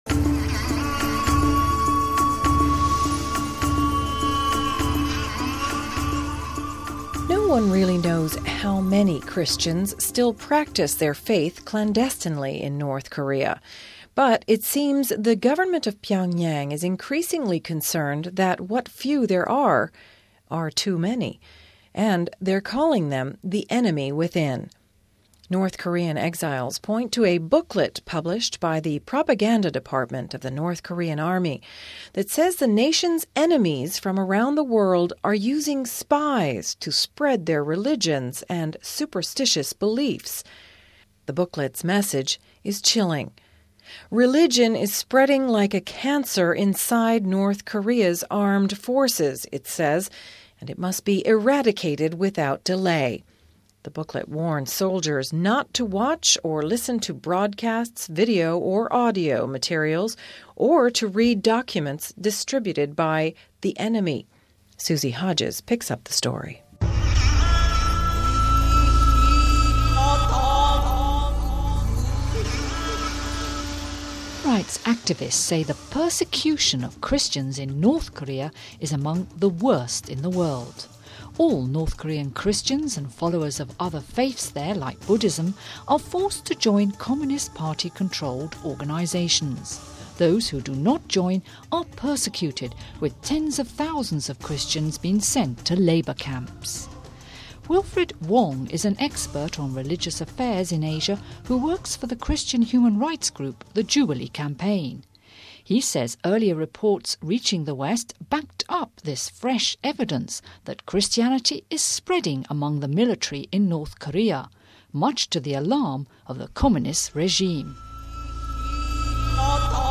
Home Archivio 2007-10-02 14:43:29 A CHURCH UNDER SEIGE We talk to a human rights activist about the dramatic situation of Christians in North Korea. How many are dying for their faith?